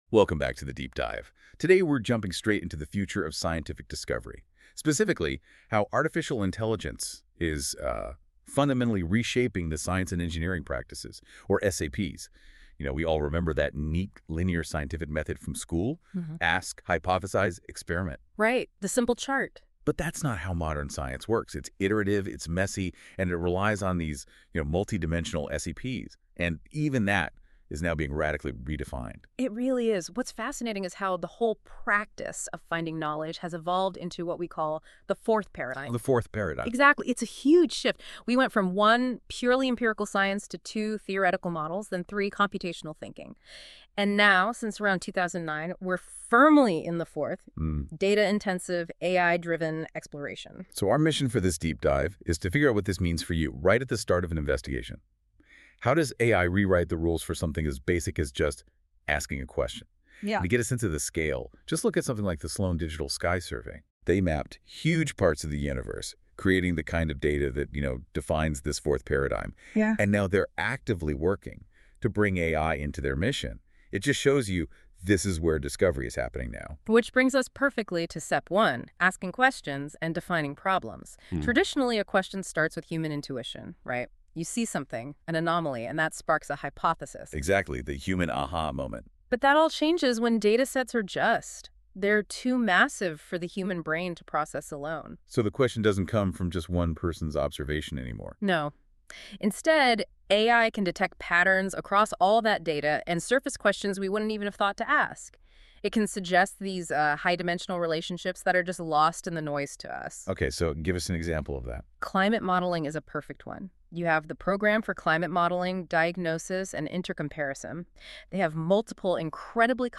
The following video and audio synopsis of this blog were generated using Google NotebookLM's features.